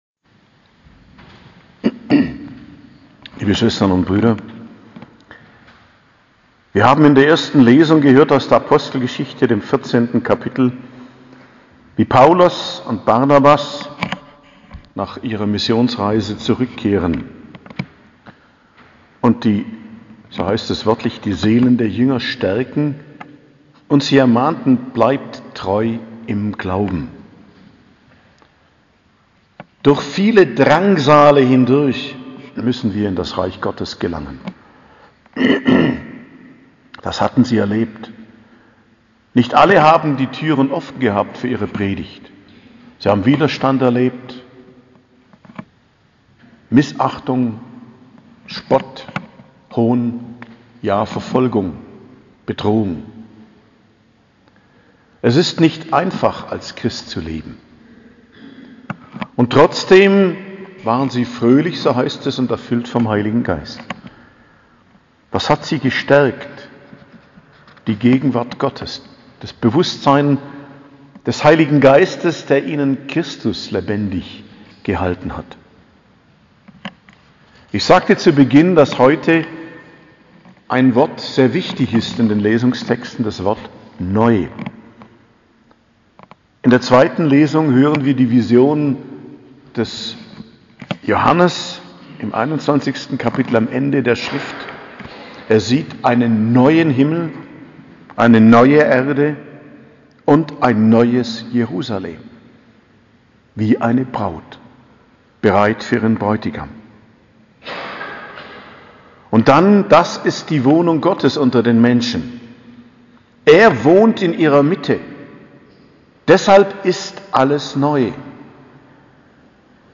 Predigt zum 5. Sonntag der Osterzeit, 15.05.2022